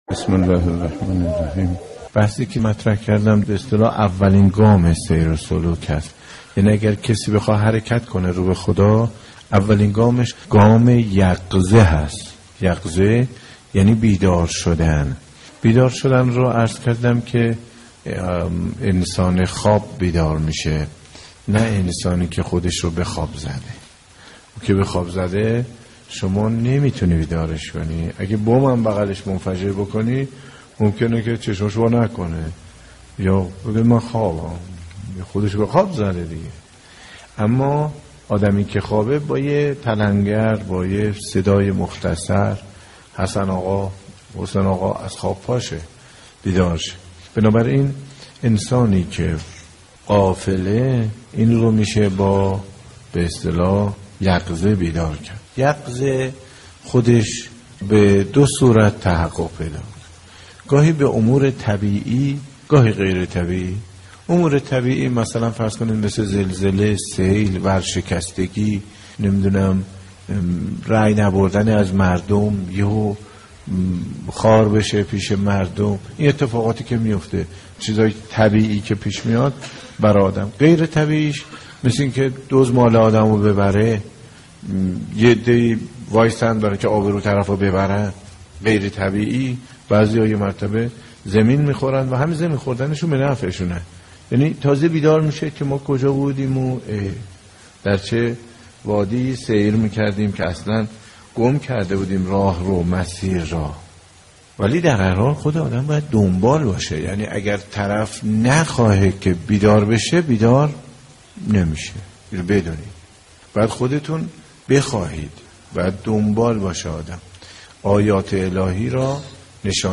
سخنرانی حجت الاسلام مرتضی آقاتهرانی با موضوع بیداری و غفلت - 2 جلسه
صوت سخنرانی مذهبی و اخلاقی